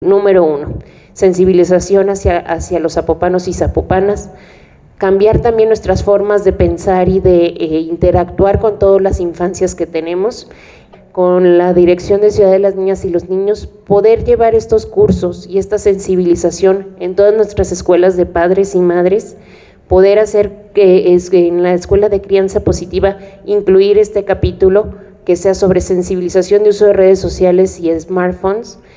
Habla Paulina Torres, Jefa de Gabinete del municipio: